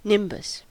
Ääntäminen
Synonyymit air halo rain cloud Ääntäminen US Tuntematon aksentti: IPA : /ˈnɪmbəs/ Haettu sana löytyi näillä lähdekielillä: englanti Määritelmät Substantiivit A circle of light ; a halo .